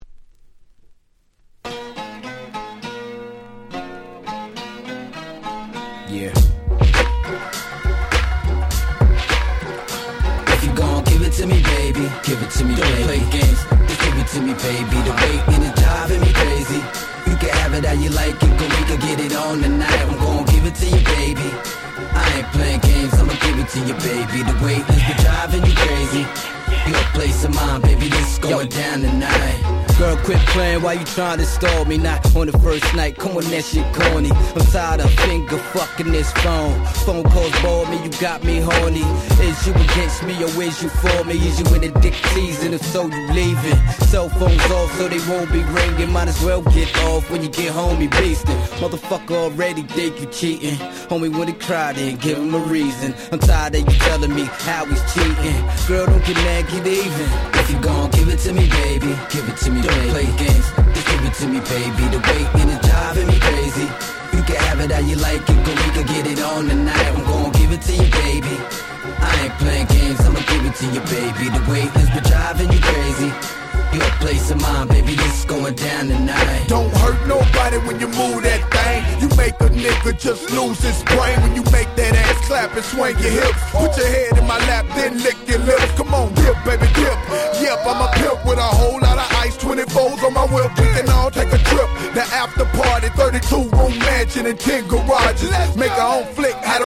06' Smash Hit Hip Hop !!